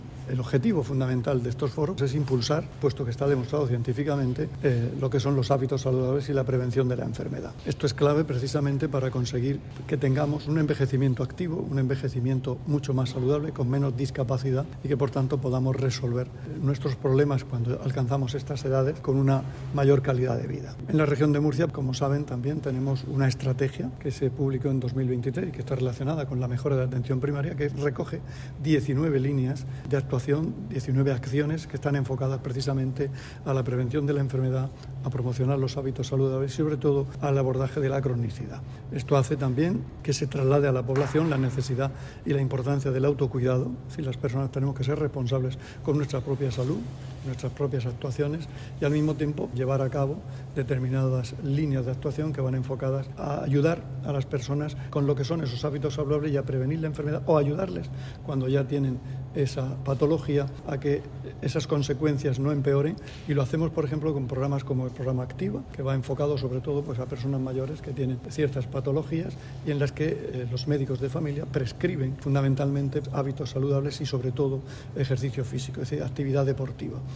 Sonido/ Declaraciones del consejero de Salud, Juan José Pedreño, en el foro sobre longevidad saludable [mp3].